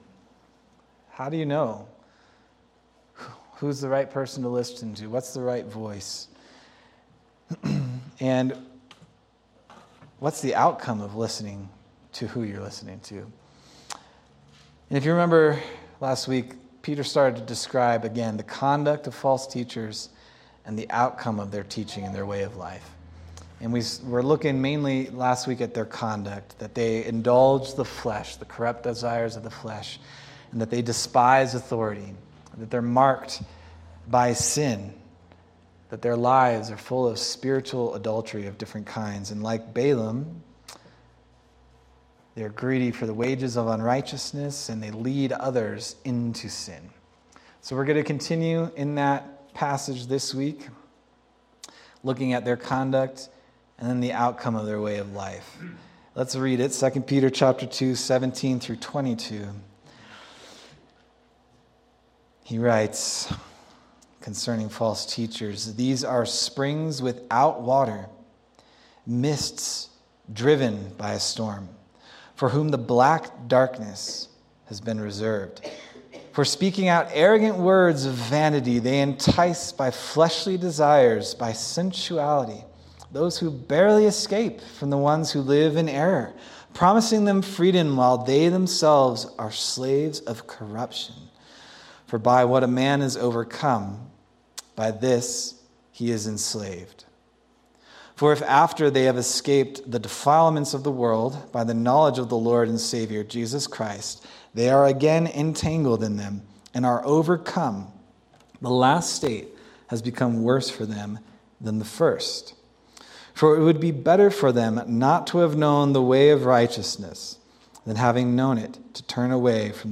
November 16th, 2025 Sermon